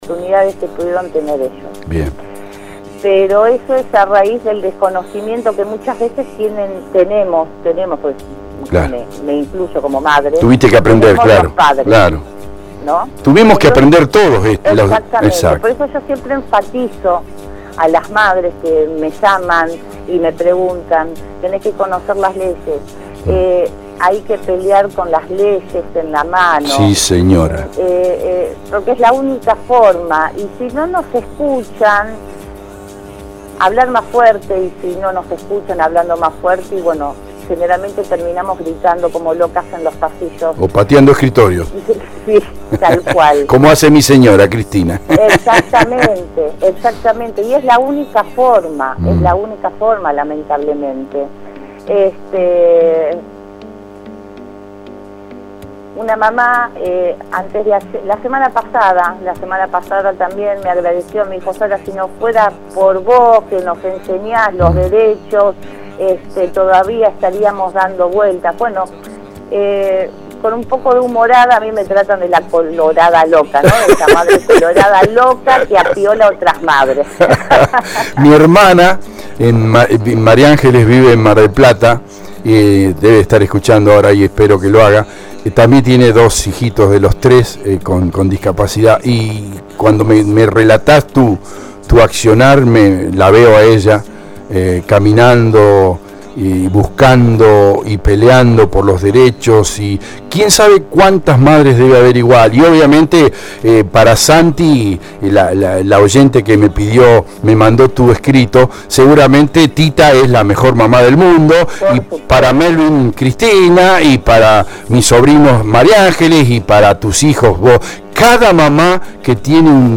Localizamos en Escobar a su autora, y la entrevistamos.
La charla duró varios minutos.